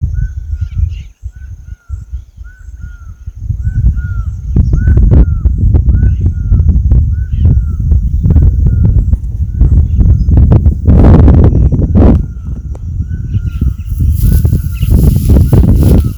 Laughing Falcon (Herpetotheres cachinnans)
Country: Argentina
Province / Department: Chaco
Condition: Wild
Certainty: Photographed, Recorded vocal